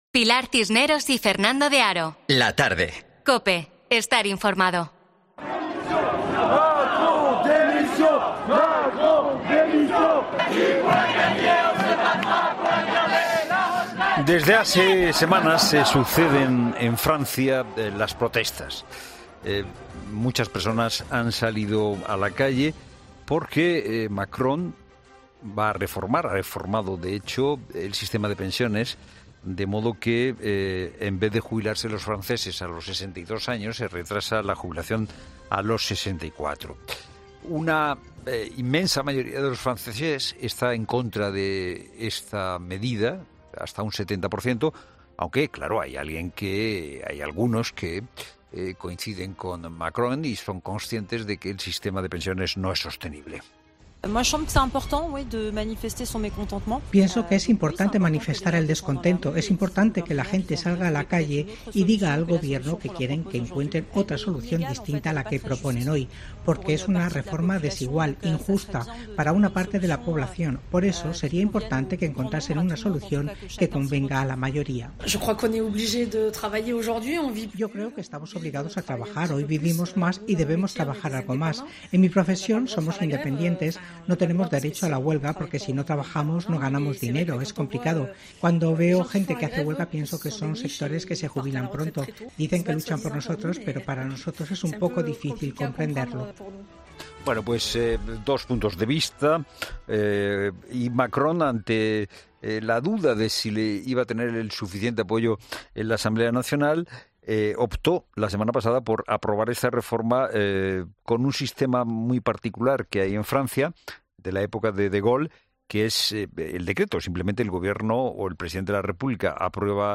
La Tarde de COPE conecta con París